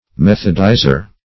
Methodizer \Meth"od*i`zer\, n. One who methodizes.